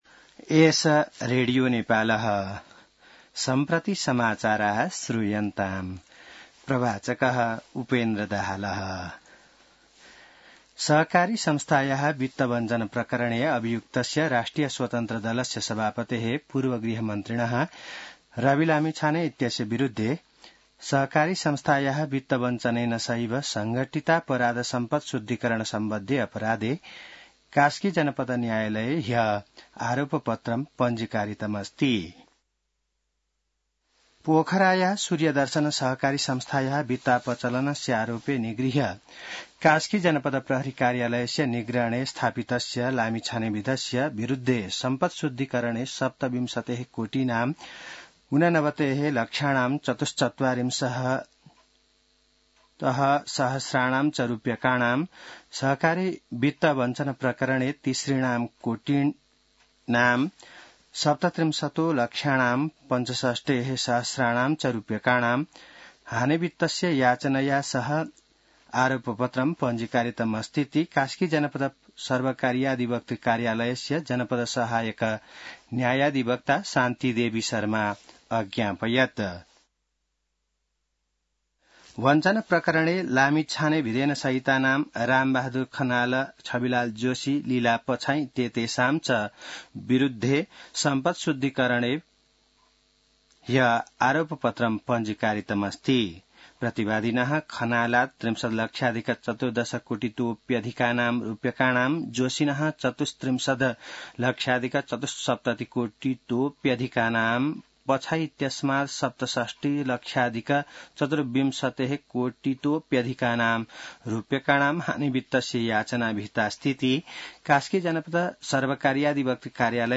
संस्कृत समाचार : ९ पुष , २०८१